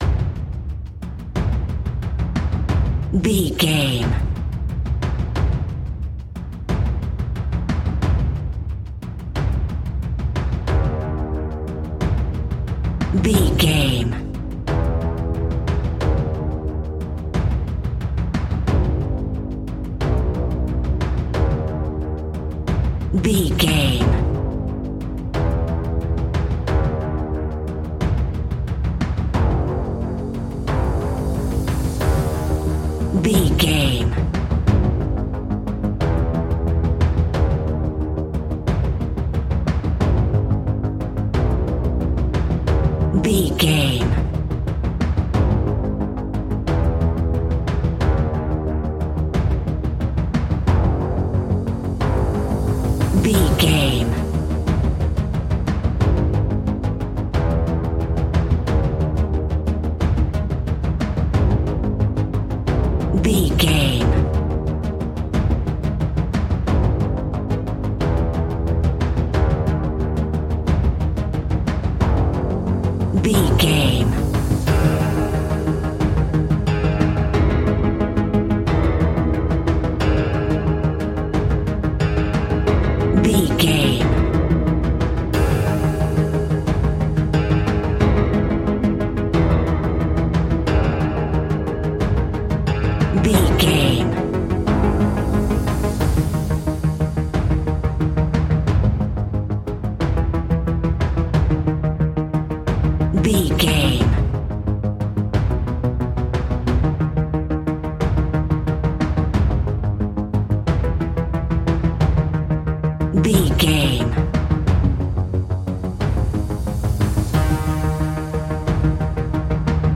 royalty free music
Aeolian/Minor
G#
ominous
dark
haunting
eerie
drums
percussion
synthesiser
ticking
electronic music